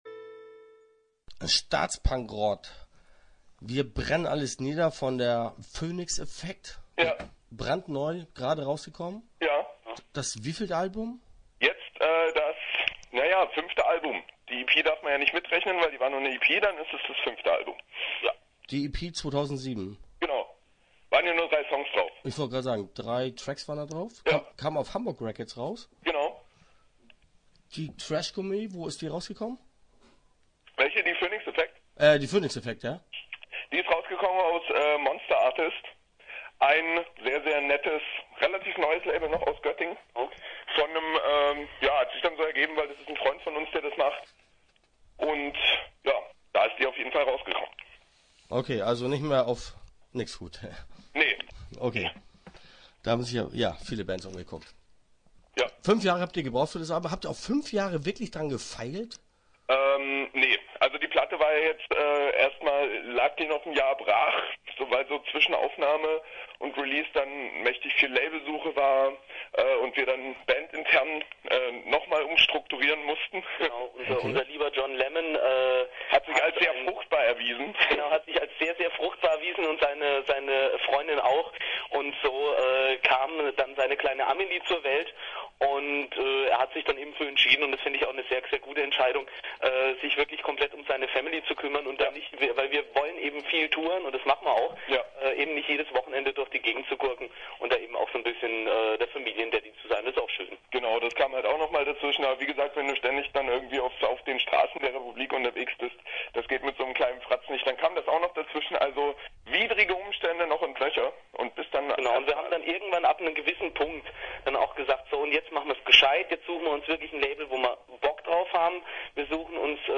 Interview Teil 1 (8:39)